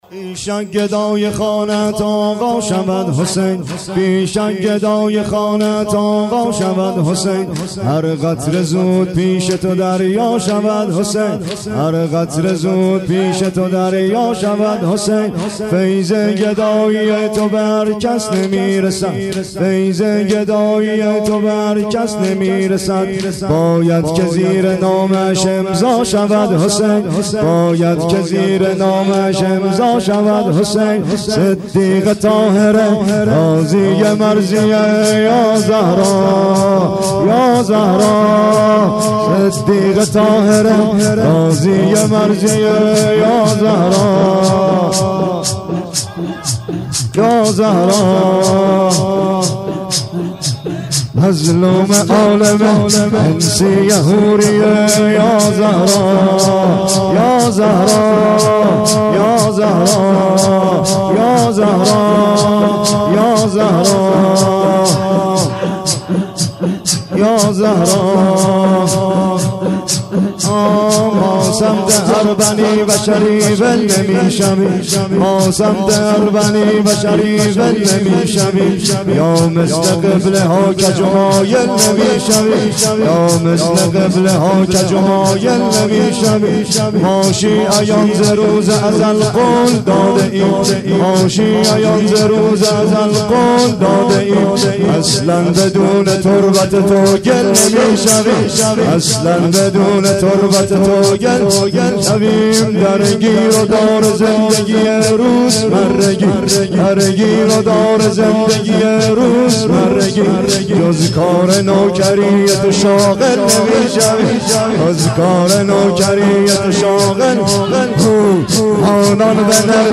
بی شک گدای خانه ات آقا شود حسین (سینه زنی/ تک